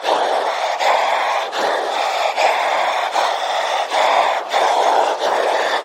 Monster Breathing Sound Effect Free Download
Monster Breathing